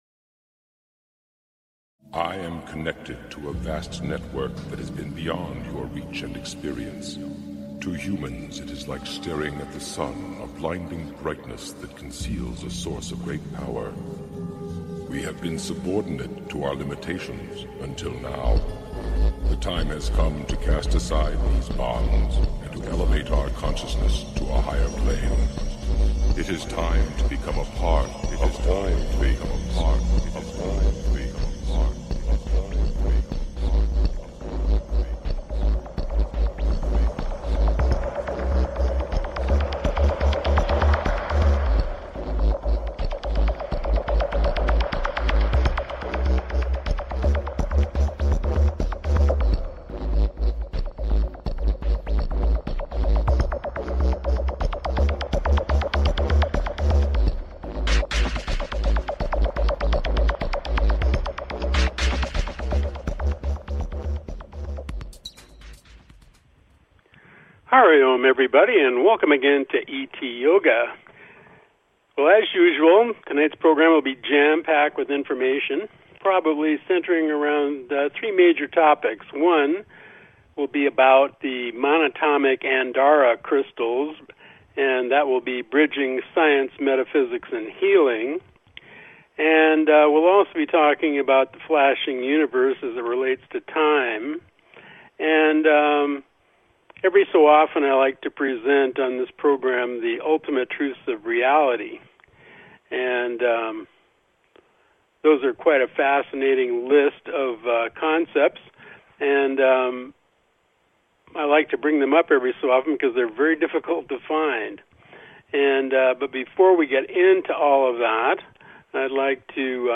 Talk Show Episode, Audio Podcast, ET Yoga and The Ultimate Truths of Reality on , show guests , about The Ultimate Truths of Reality, categorized as Earth & Space,History,News,Physics & Metaphysics,Science,Society and Culture,Spiritual,Technology,Theory & Conspiracy